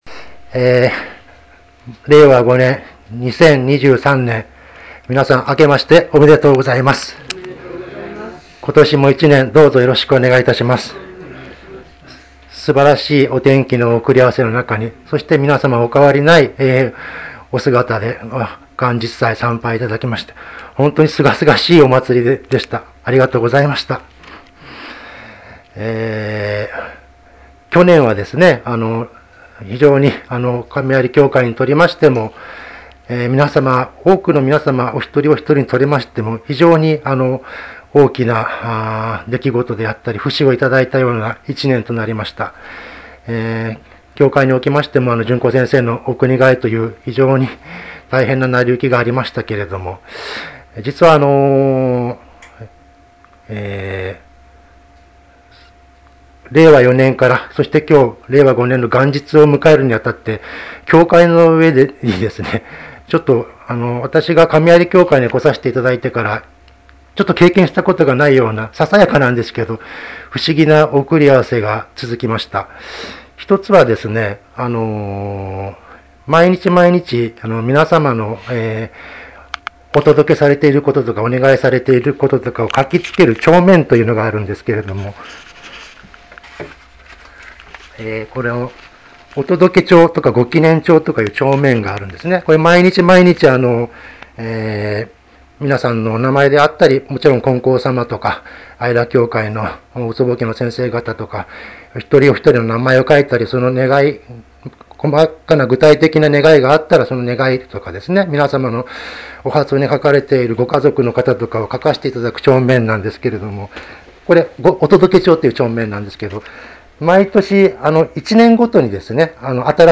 投稿ナビゲーション 過去の投稿 前 元日祭